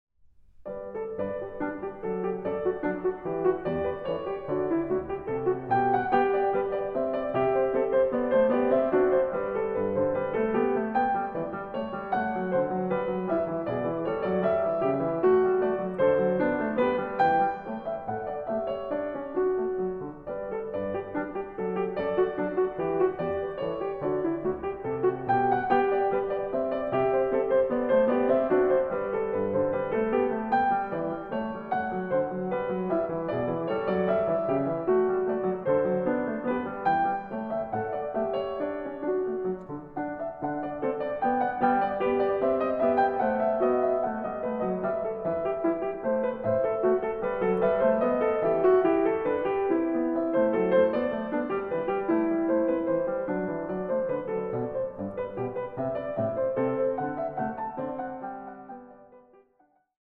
a 1 Clav.